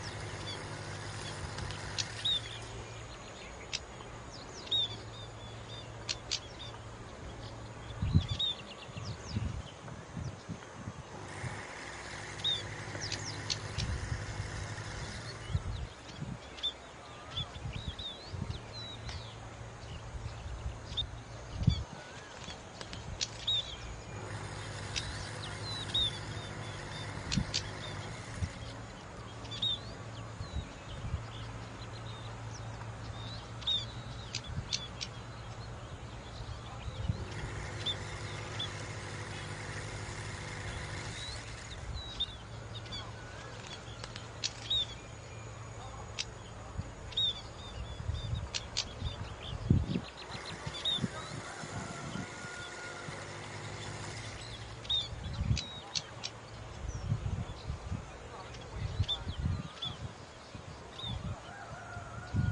斑文鸟吃草籽时发出的叫声